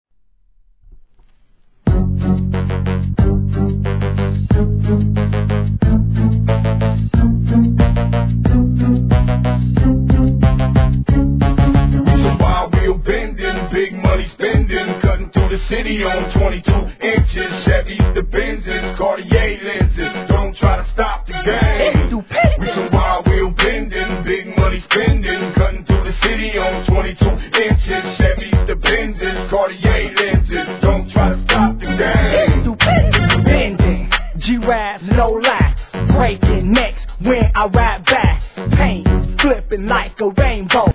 G-RAP/WEST COAST/SOUTH
ダークな旋律に息のぴったりな掛け合いでの良質激マイナーG！！